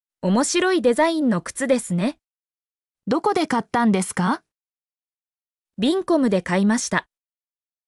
mp3-output-ttsfreedotcom-4_Nkm6eNym.mp3